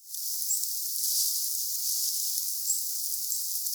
että hippiäinen ruovikossa.